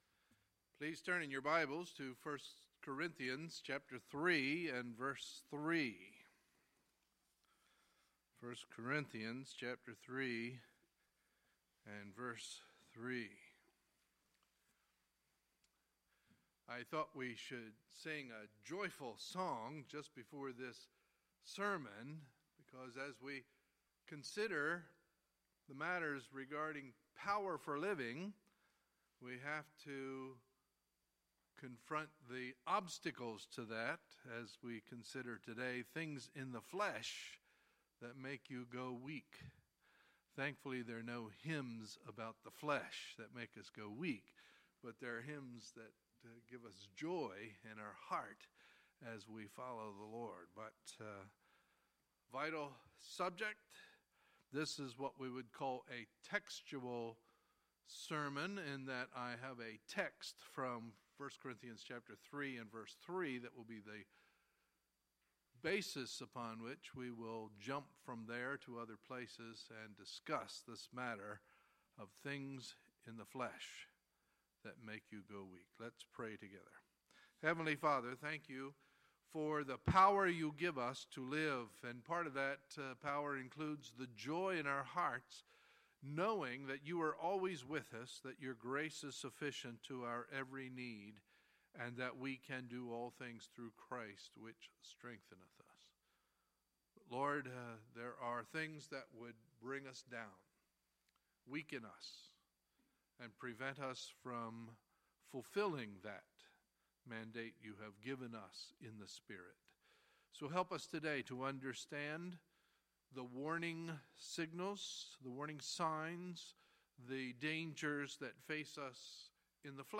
Sunday, February 12, 2017 – Sunday Morning Service